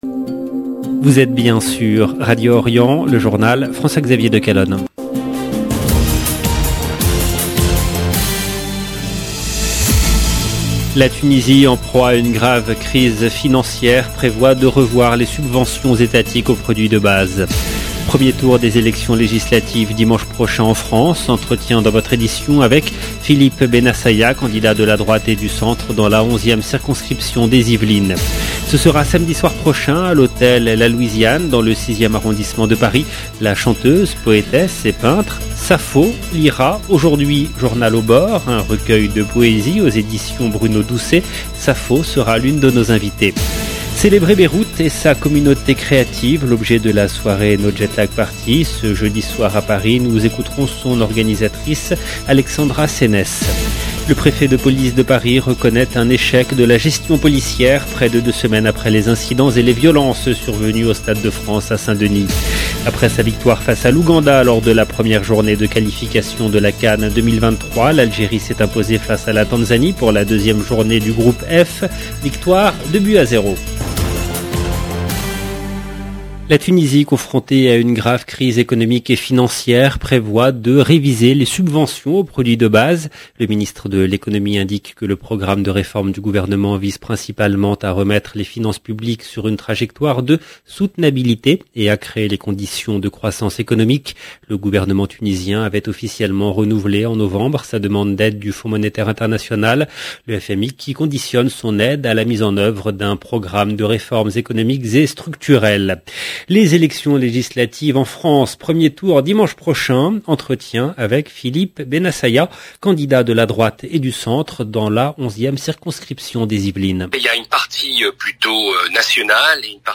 LB JOURNAL EN LANGUE FRANÇAISE
Entretien dans votre édition avec Philippe Benassaya, candidat de la droite et du centre dans la 11e circonscription des Yvelines.